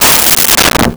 Metal Strike 05
Metal Strike 05.wav